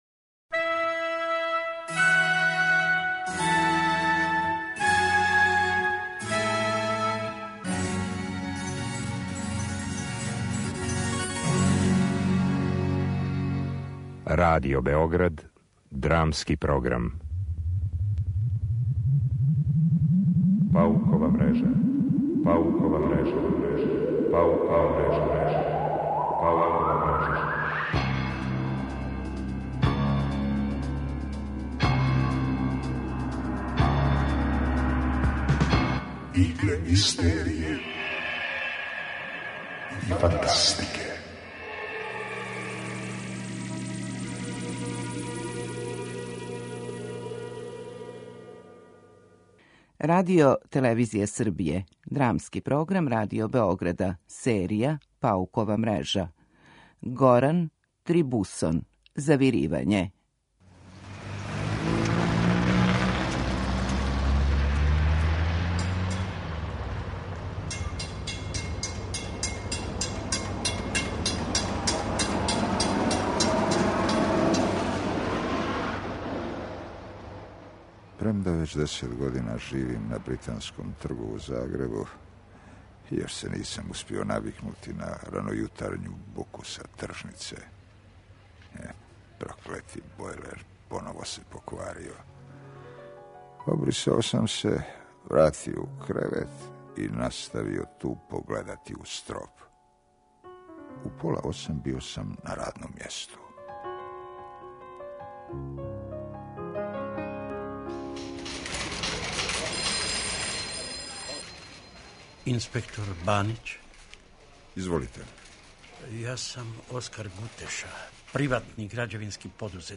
Драмски програм: Паукова мрежа